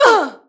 m_721_hit_01.wav